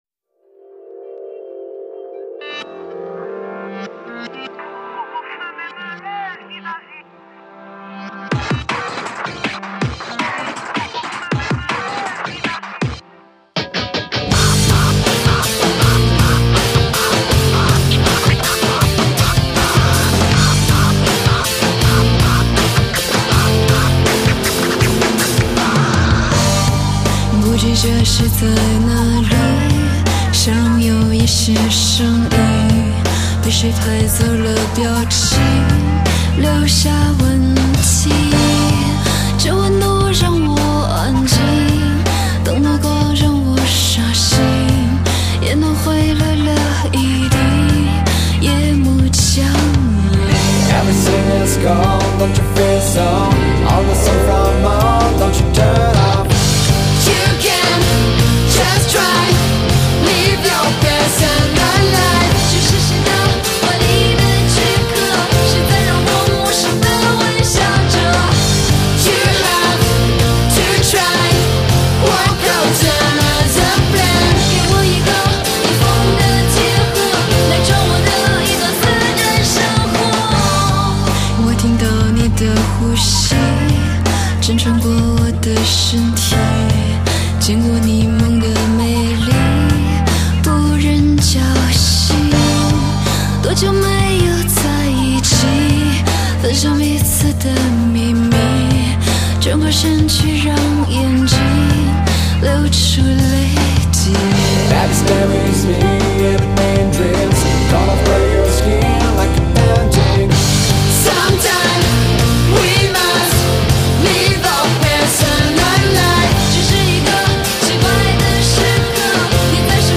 Neo-Electro-Rock